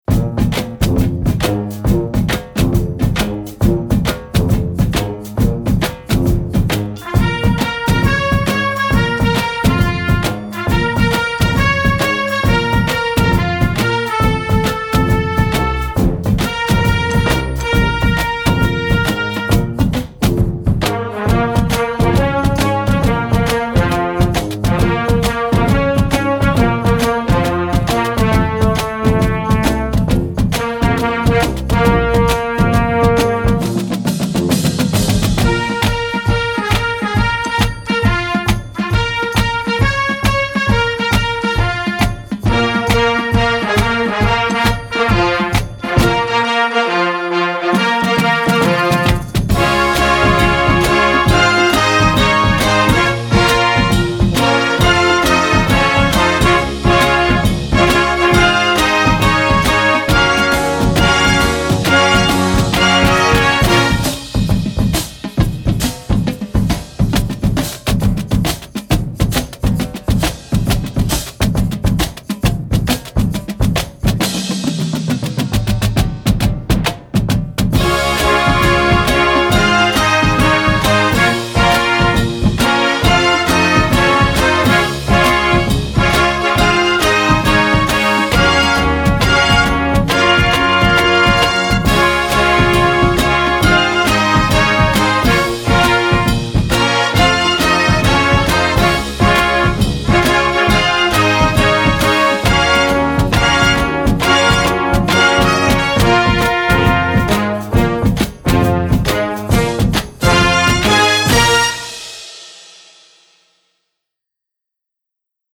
Gattung: Marching Band Series
Besetzung: Blasorchester